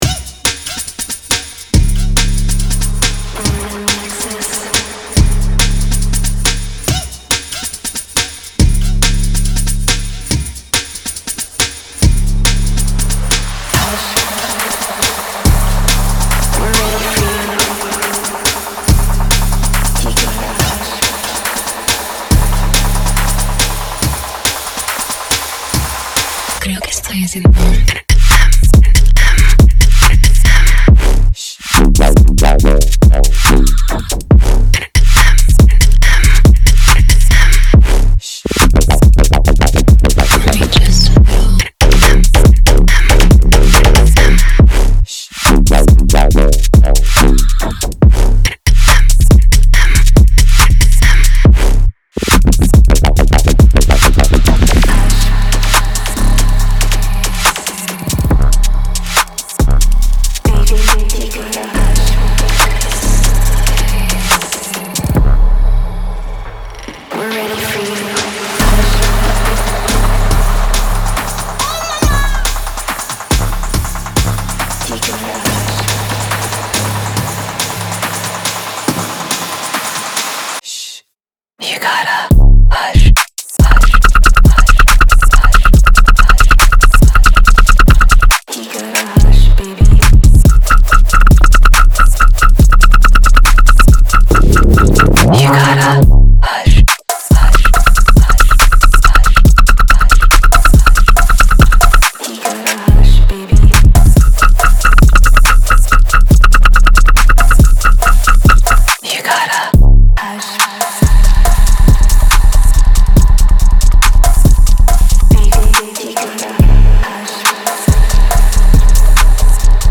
• Жанр: Dubstep